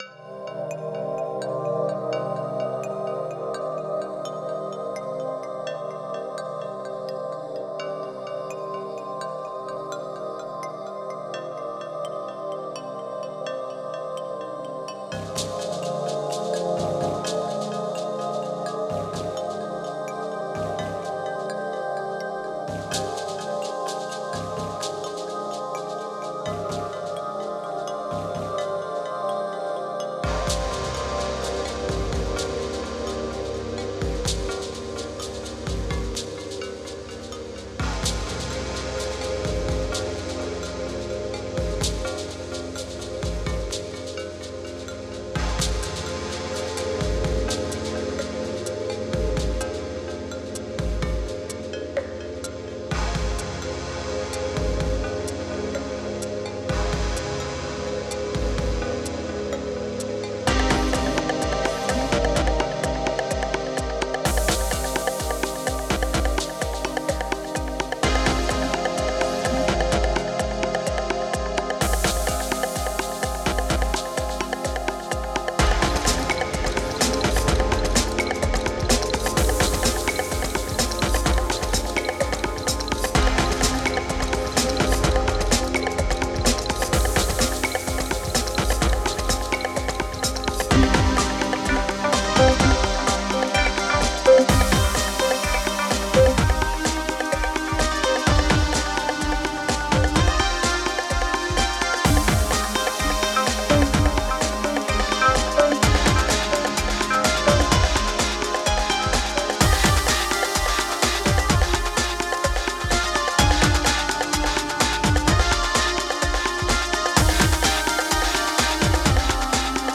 Genre: IDM, Electronic.